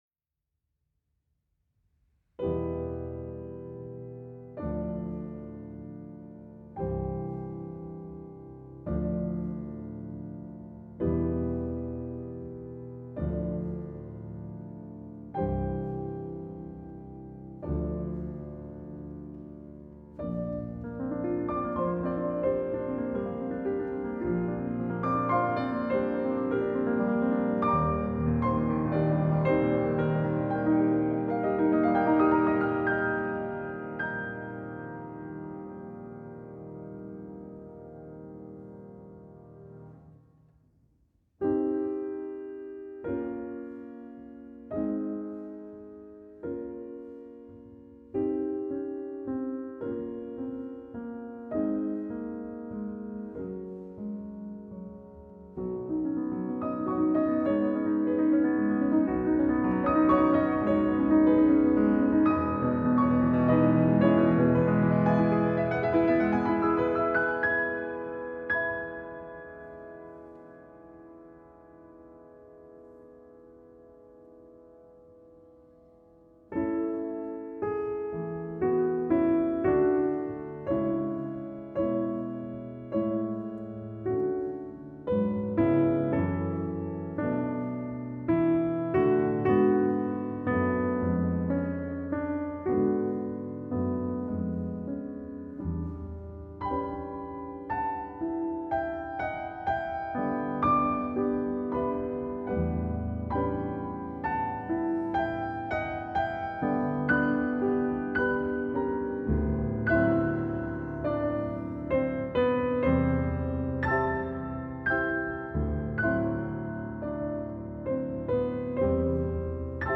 Classical, Piano